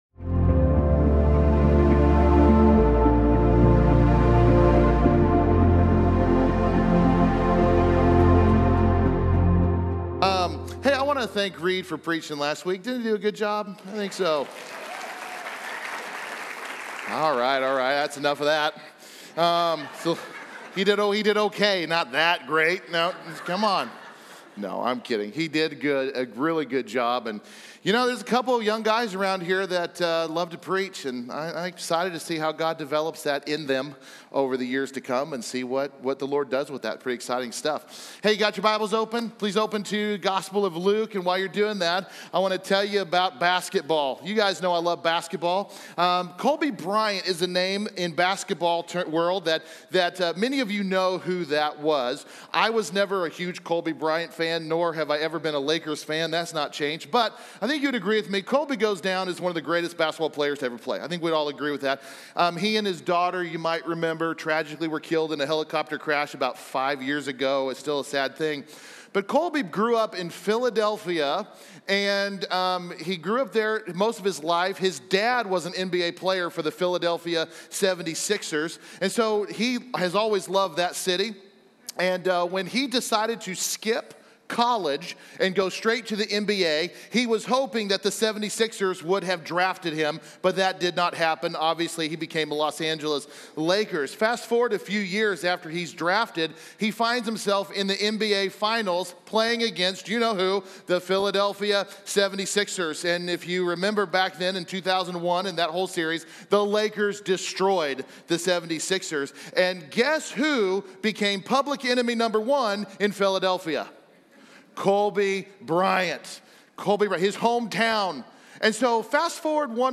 In today's sermon, we reflect on Jesus' return to Nazareth, where He declares Himself as the fulfillment of Isaiah's prophecy but faces rejection from those familiar with Him. This message challenges us to examine our own responses to Jesus, encouraging us to move beyond mere familiarity and truly embrace Him as the Messiah in our daily lives.